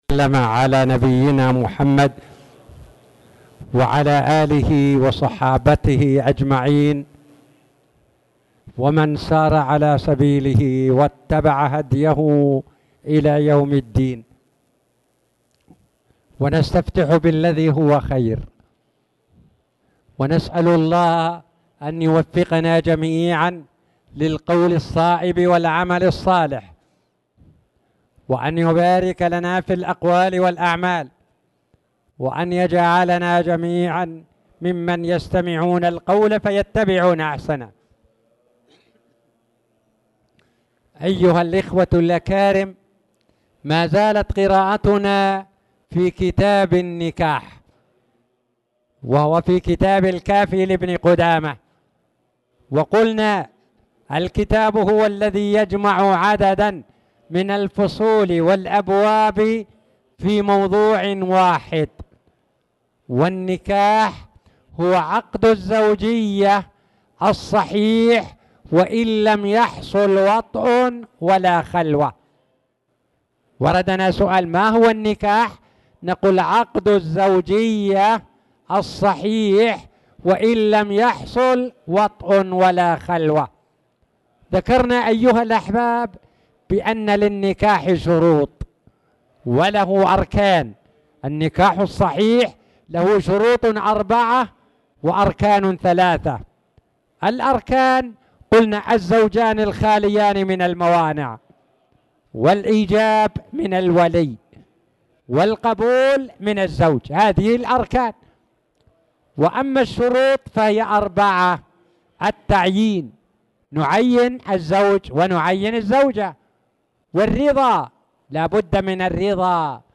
تاريخ النشر ٢٠ ربيع الثاني ١٤٣٨ هـ المكان: المسجد الحرام الشيخ